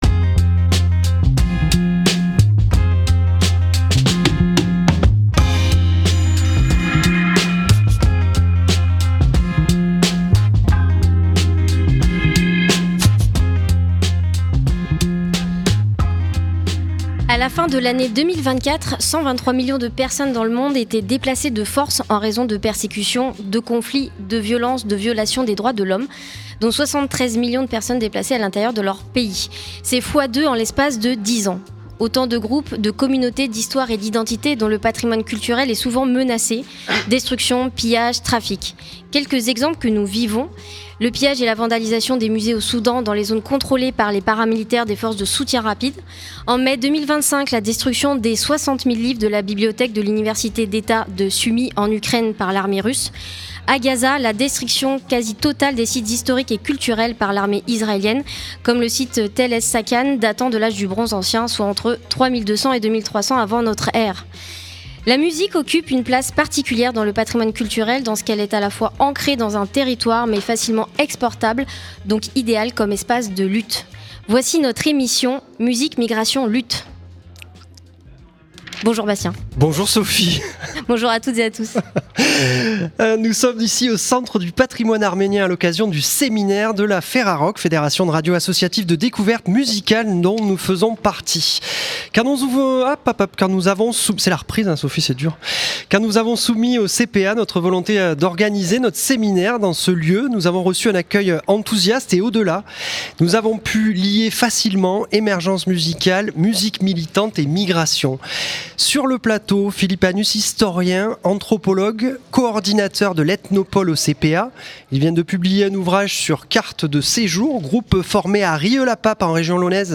On y parle immigration, luttes sociales et musique aux cotés de Radio Dio et La Clé Des Ondes pour revenir à la place de la radio dans ses sujets sociétaux. Emission en public au Centre du Patrimoine Arménien.